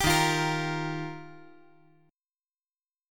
Ebadd9 Chord
Listen to Ebadd9 strummed